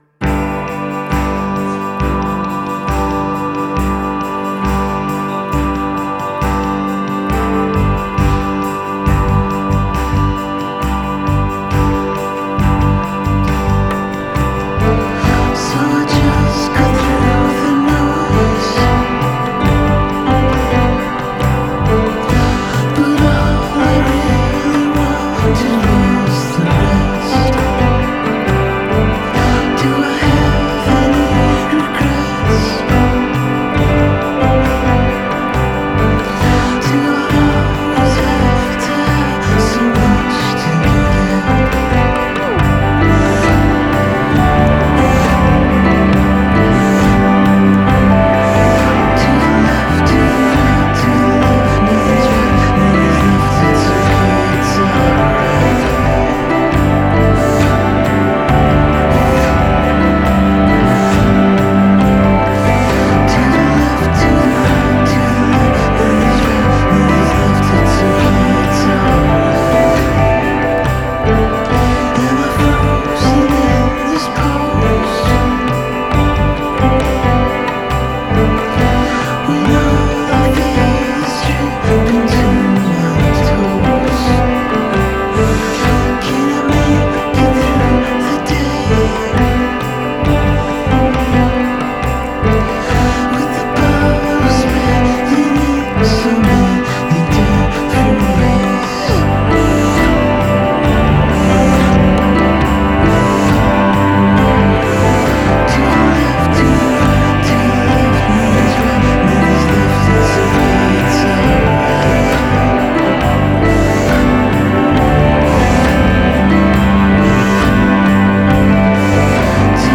guitars
bass
drums
trumpet
cello